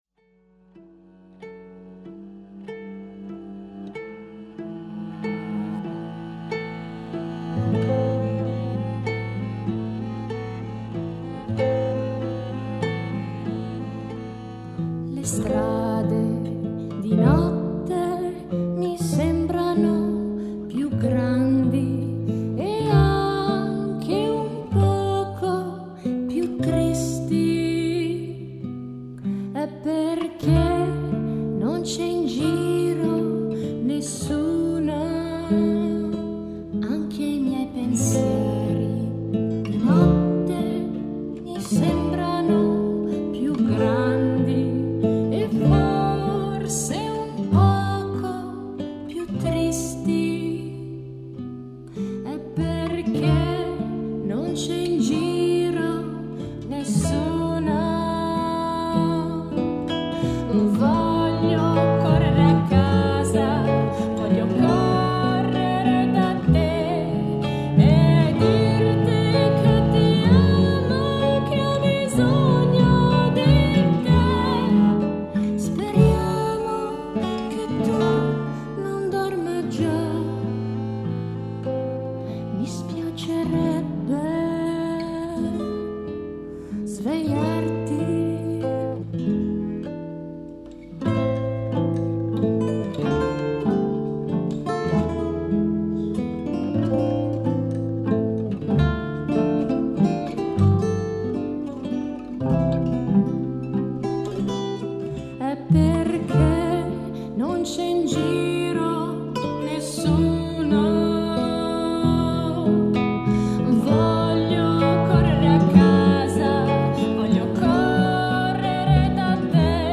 Reprise